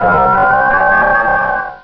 Cri de Milobellus dans Pokémon Rubis et Saphir.